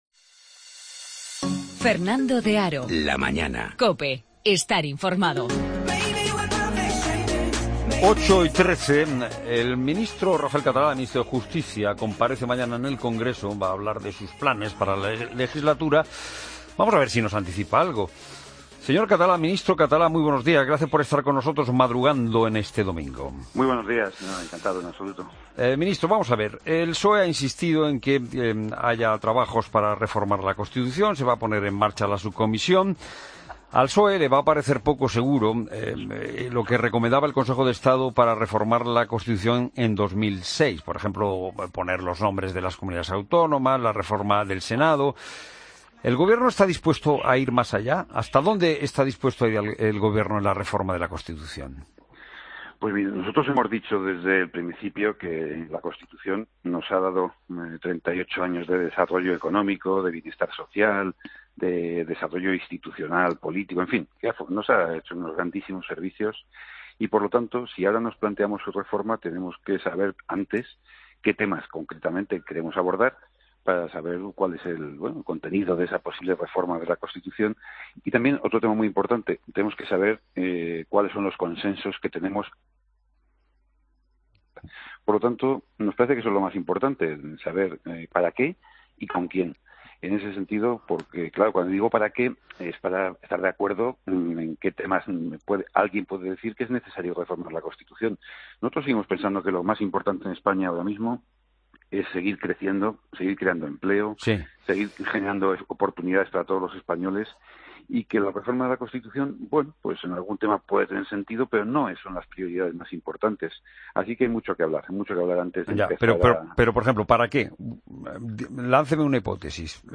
Escucha al ministro de Justicia, Rafael Catalá, en 'La Mañana de fin de semana'